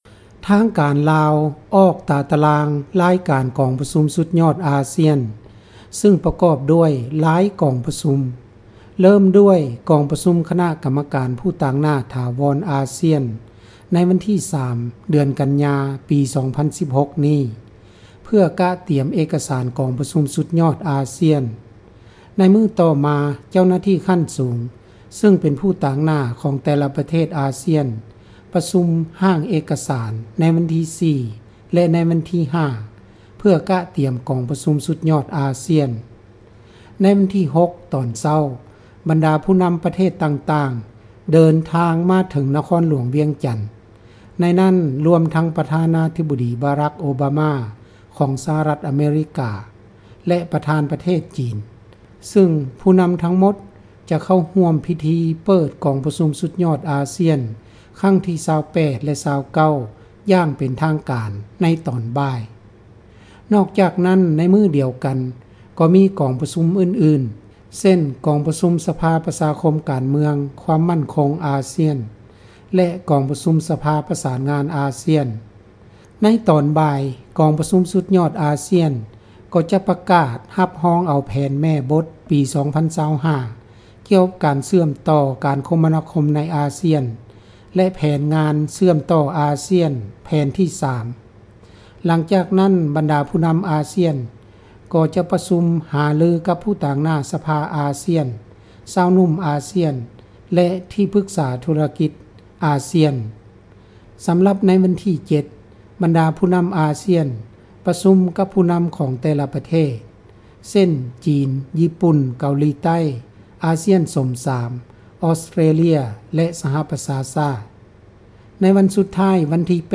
ຣາຍງານຈາກ ນະຄອນຫລວງວຽງຈັນ